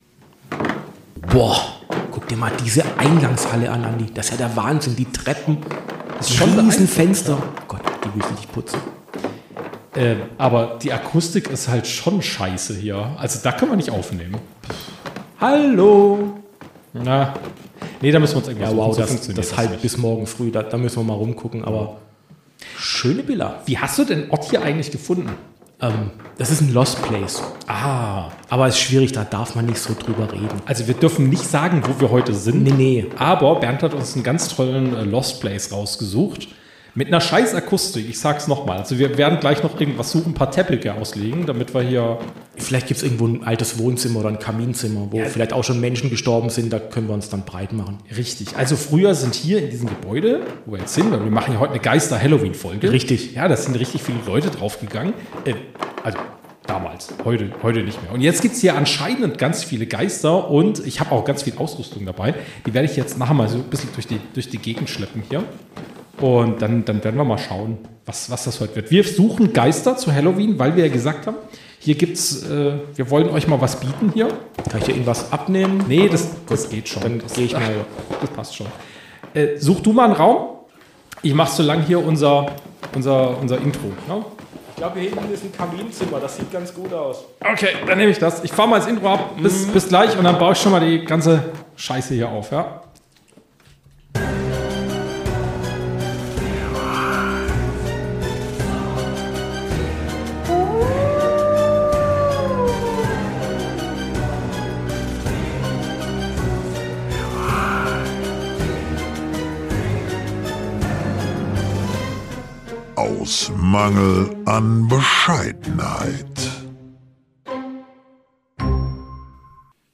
Beschreibung vor 5 Monaten In dieser ganz besonderen Halloween-Episode von Aus Mangel an Bescheidenheit haben wir uns in ein altes, angeblich verfluchtes Spukhaus gewagt – ausgestattet mit allerlei fragwürdiger Geisterjäger-Technik und einer ordentlichen Portion Selbstironie. Während wir auf paranormale Erscheinungen warteten, haben wir über Halloween gesprochen: Woher kommt der Brauch eigentlich? Wie wird er in anderen Ländern gefeiert?